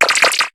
Cri d'Araqua dans Pokémon HOME.